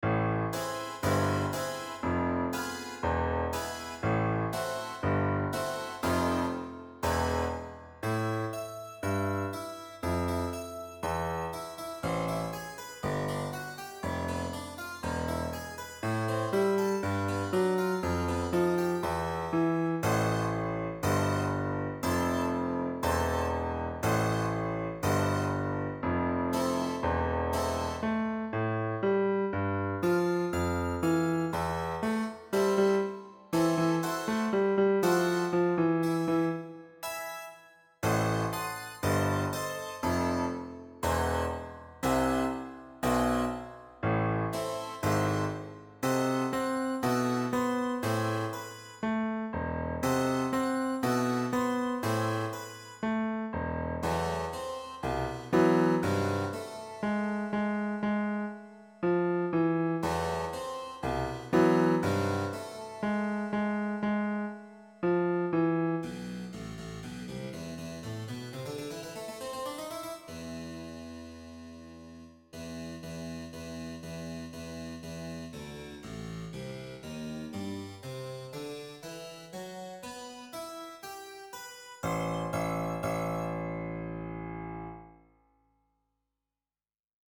GenereBlues
Che bell'atmosfera magica e misteriosa!